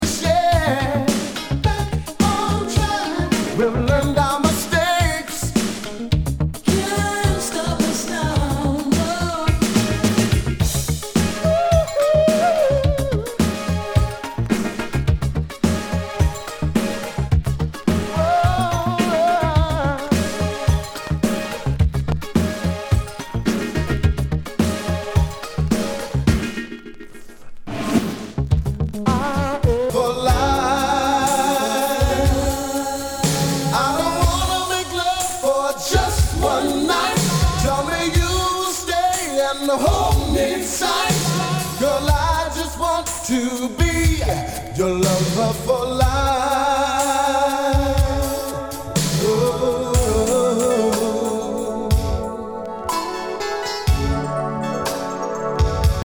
HOUSE/TECHNO/ELECTRO
ナイス！シンセ・ポップ / ヴォーカル・ハウス！
全体にチリノイズが入ります。